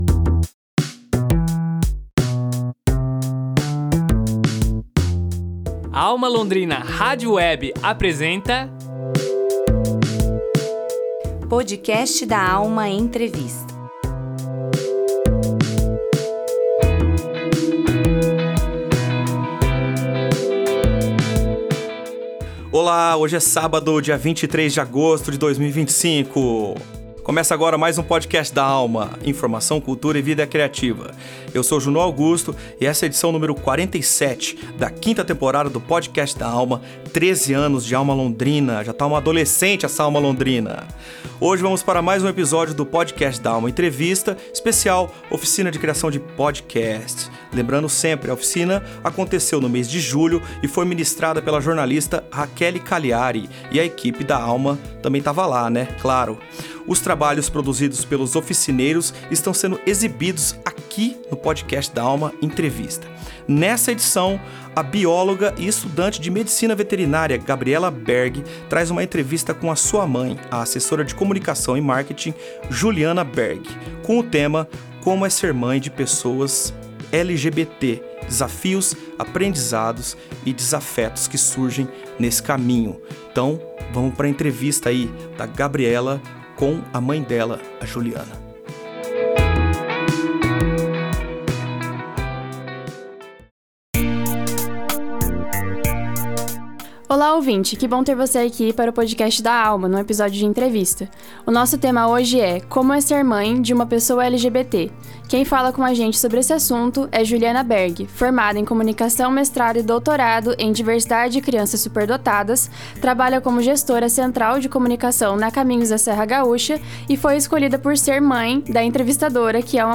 Acervo Alma Londrina Neste episódio do Podcast da Alma Entrevista